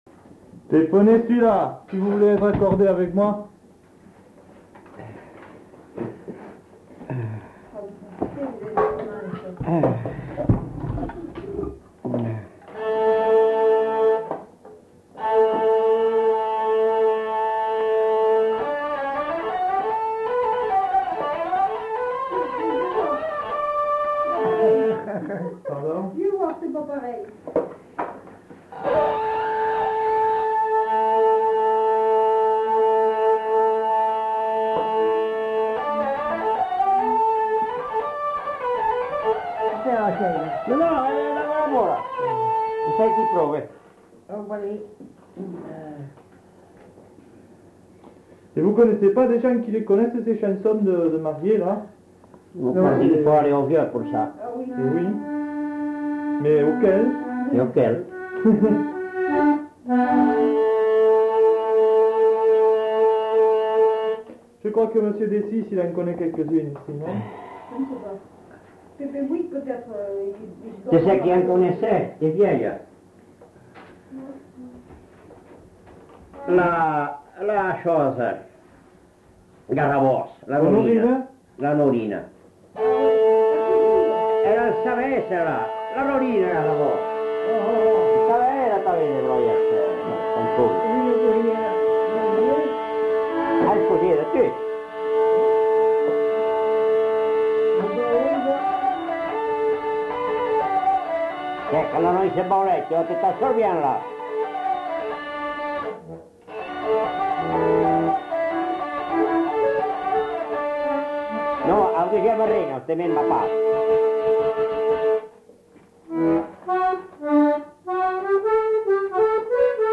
Genre : morceau instrumental
Instrument de musique : accordéon diatonique ; vielle à roue
Danse : rondeau